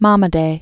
(mŏmə-dā), N(avarro) Scott Born 1934.